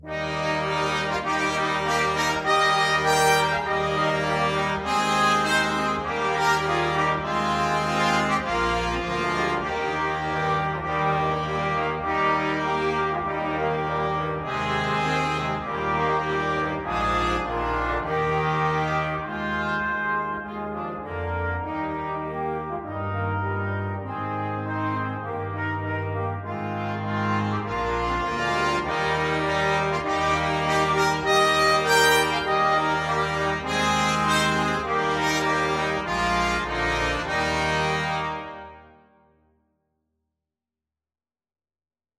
Eb major (Sounding Pitch) (View more Eb major Music for Brass Quintet )
2/2 (View more 2/2 Music)
Molto energico =c.100
Brass Quintet  (View more Intermediate Brass Quintet Music)
Traditional (View more Traditional Brass Quintet Music)